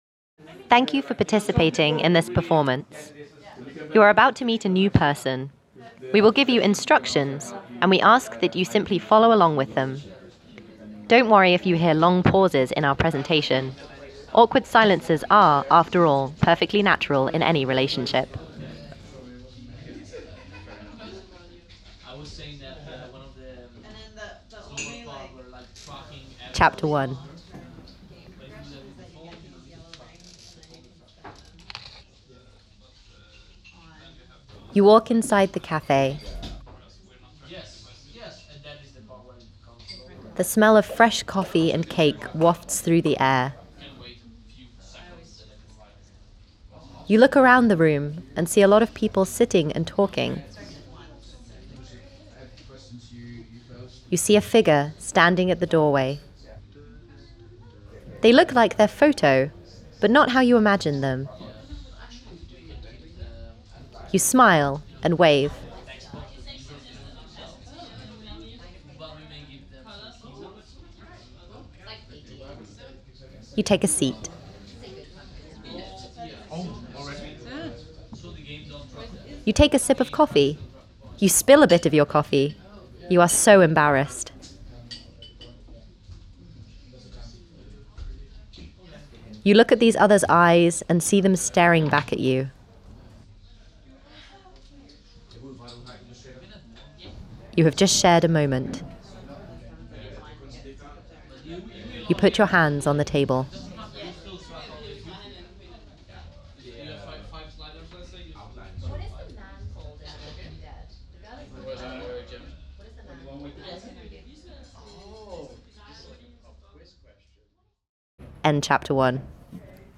The two tracks start similar, but over time the perspective offered by the two narrators changes.
Adding some diagetic audio to flesh out a sense of place made a difference to this, but our biggest change was to add an intro segment priming players for the long silences.